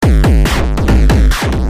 Tag: 140 bpm Glitch Loops Drum Loops 295.55 KB wav Key : Unknown